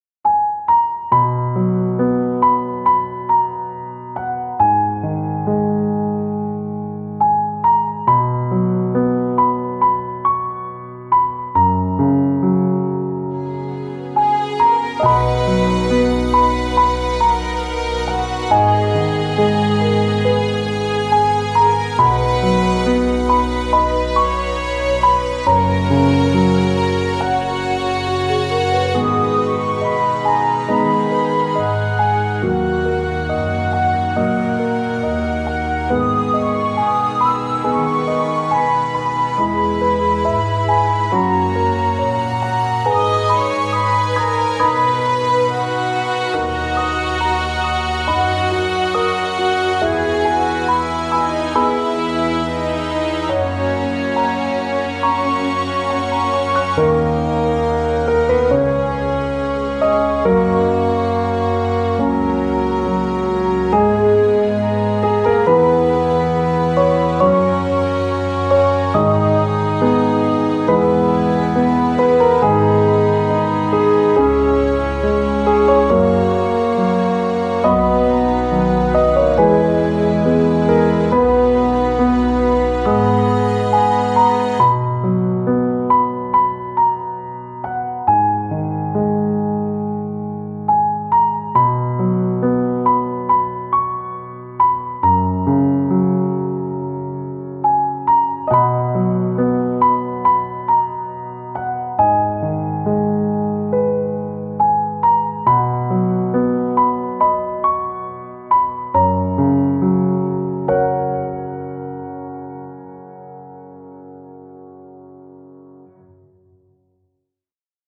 穏やかな雰囲気のある、ピアノを中心としたBGMです。
フルートやバイオリンも合わさって優しい雰囲気が終始流れていきます。
ピアノ ストリングス バラード ヒーリング
切ない 静か 優しい 癒し 落ち着く 幻想的 感動 穏やか